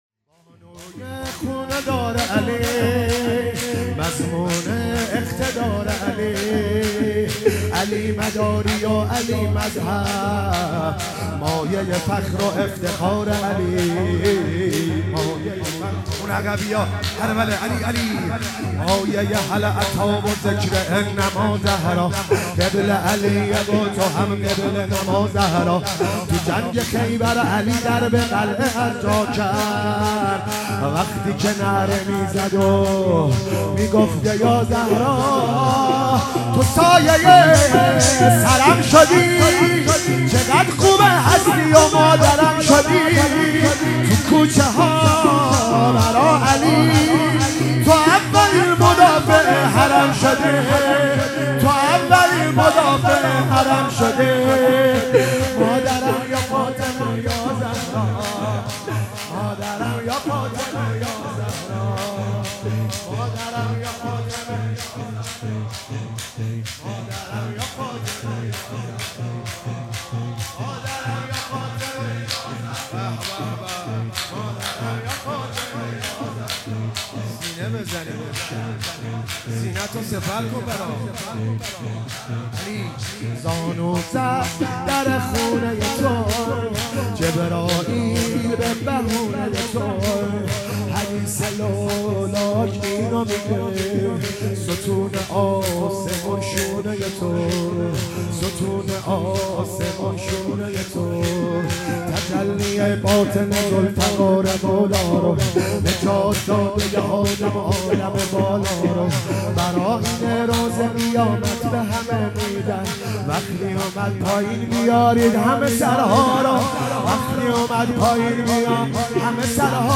فاطمیه 97 - شب سوم - شور - بانوی خونه دار علی